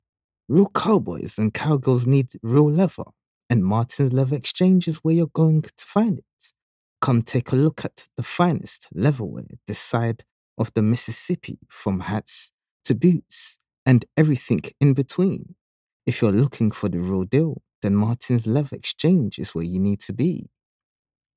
Never any Artificial Voices used, unlike other sites.
Authentic and native British speakers bring a professional, rich, refined and smooth sound to your next voice project.
English (Caribbean)
Yng Adult (18-29) | Adult (30-50)